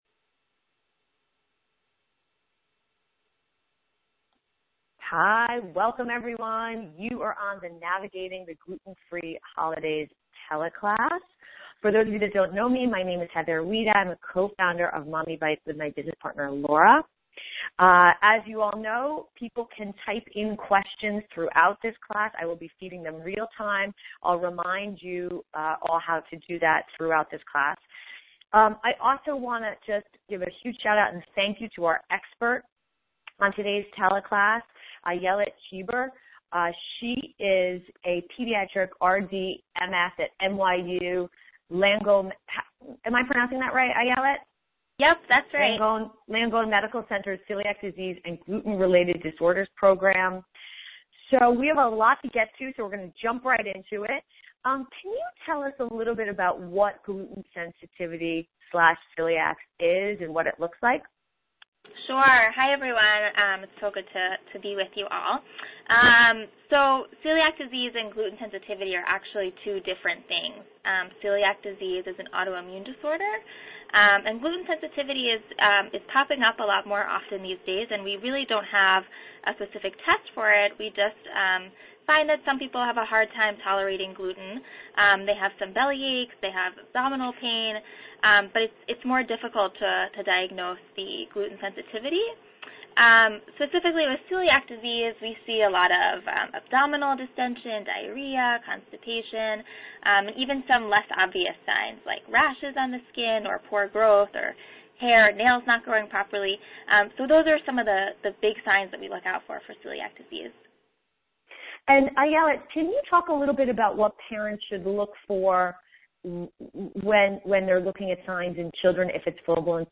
In case you missed it, you can listen to the teleclass recording here .